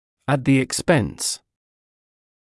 [ət ðɪ ɪk’spens][эт зи ик’спэнс]за счёт
at-the-expense.mp3